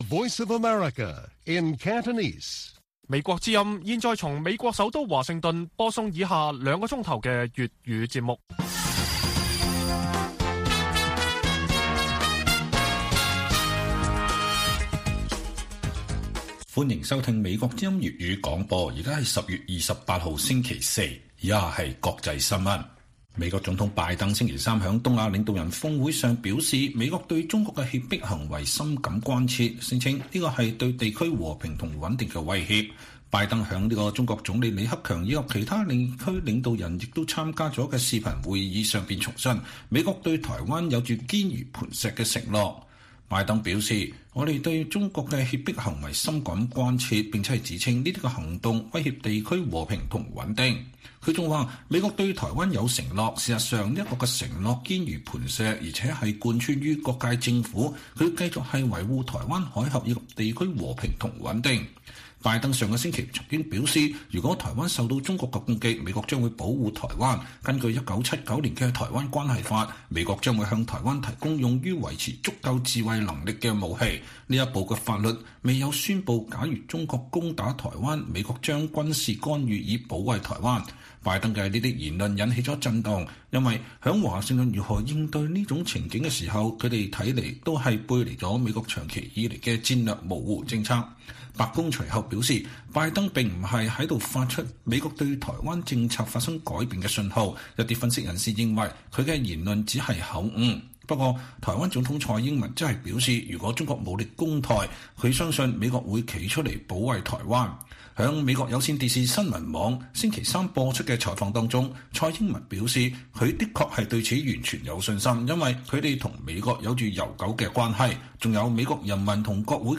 粵語新聞 晚上9-10點: 蔡英文對美國保衛台灣完全有信心